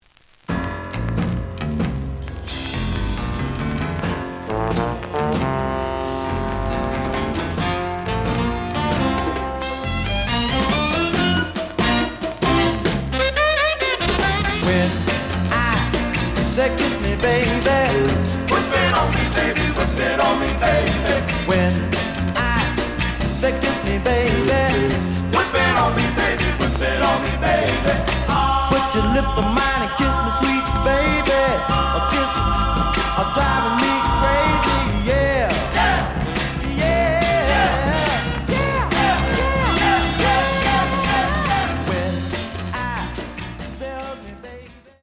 The audio sample is recorded from the actual item.
●Genre: Soul, 60's Soul
Some click noise on both sides.)